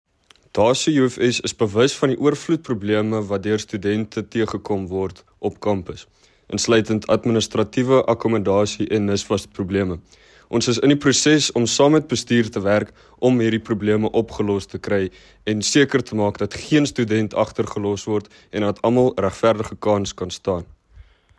Afrikaans soundbite